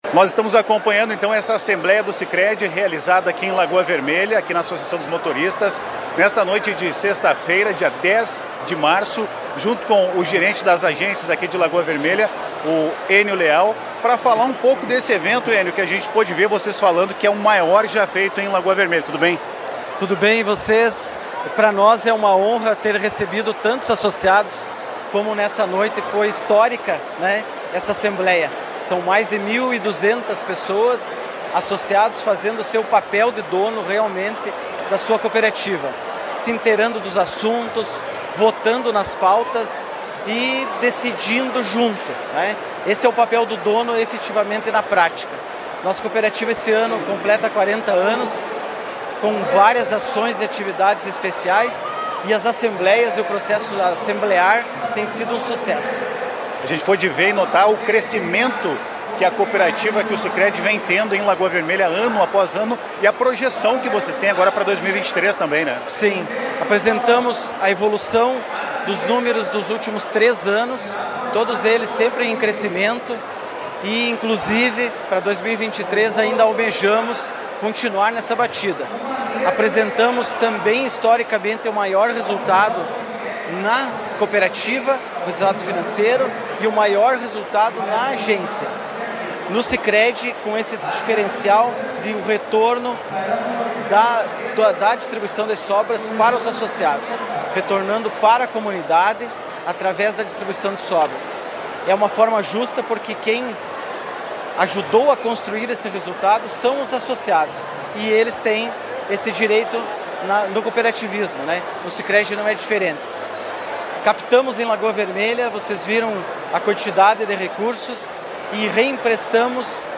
A Reportagem da Tua Rádio Cacique esteve acompanhando o evento.